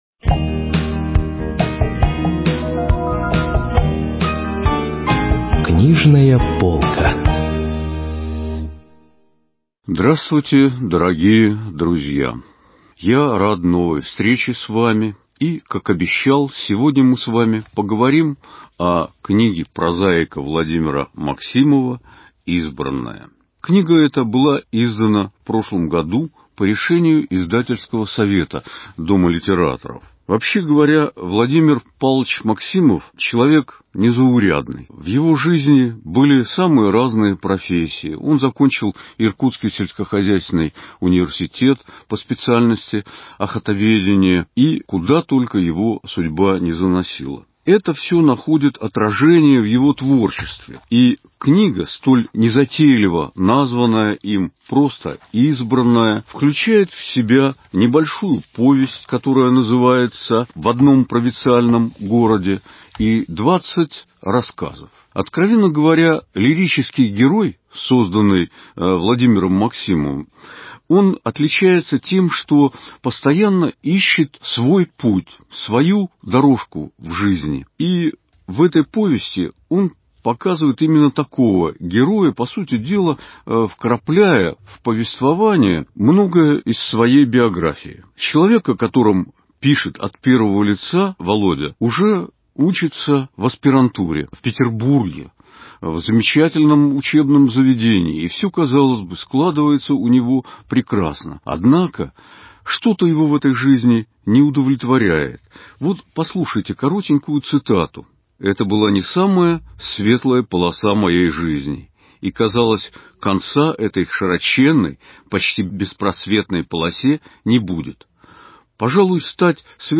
радио-презентация